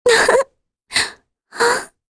Talisha-Vox_Sad_kr_1.wav